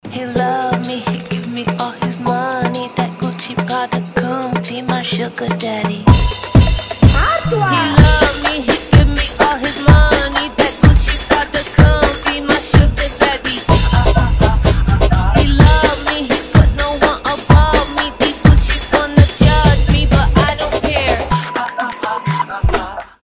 восточные
ремиксы